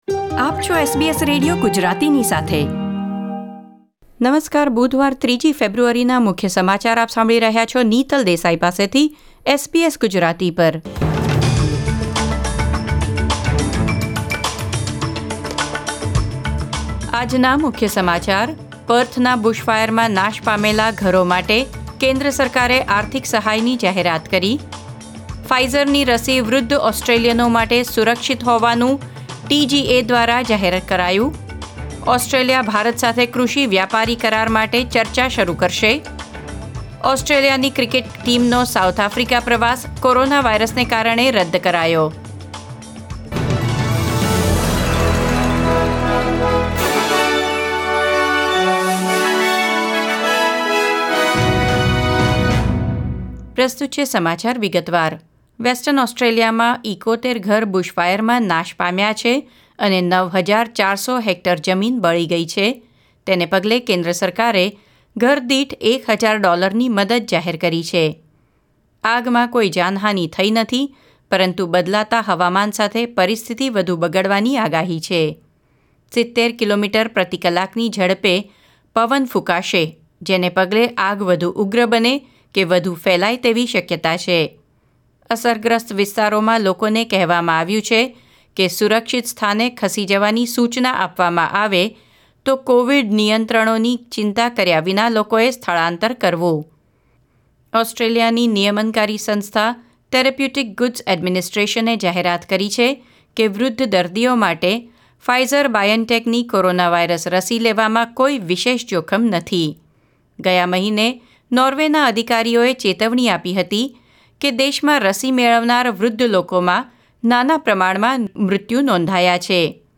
SBS Gujarati News Bulletin 3 February 2021